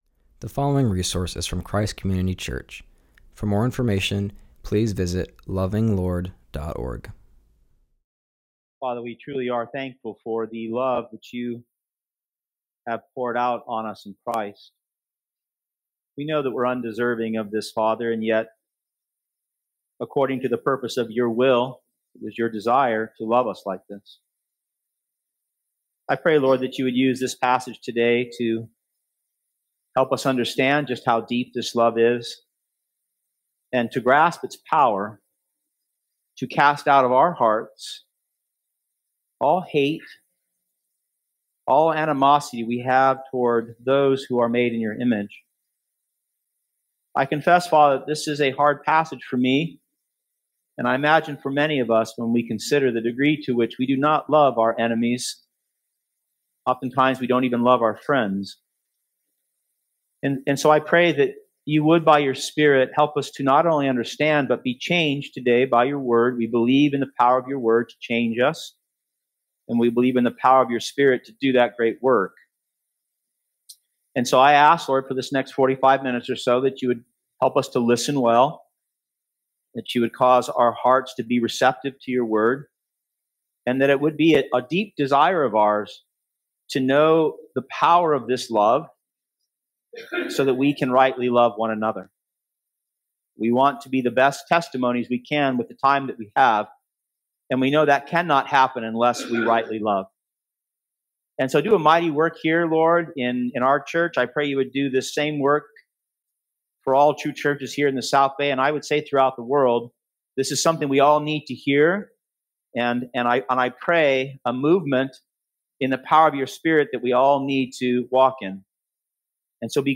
continues our series and preaches from Ephesians 1:11-14.